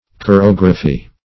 Chorography \Cho*rog"ra*phy\, n. [L. chorographia, Gr.